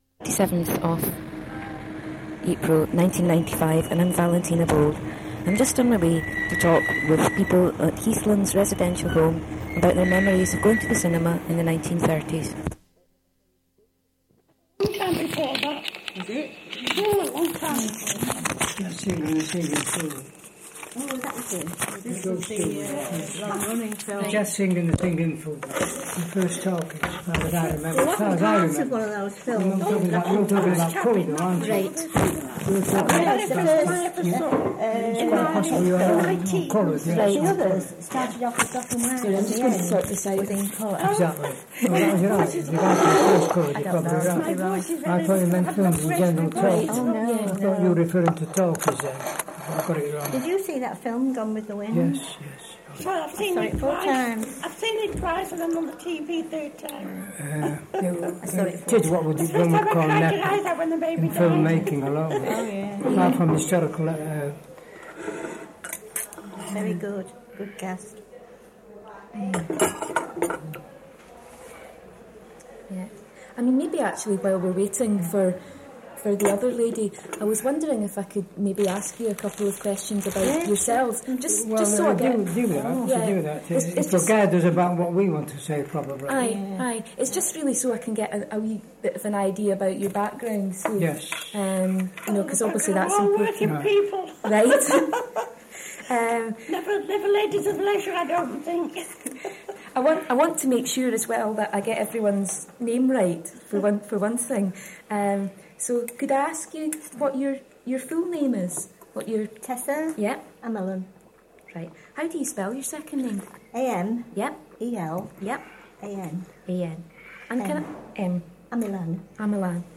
Sound Quality: Good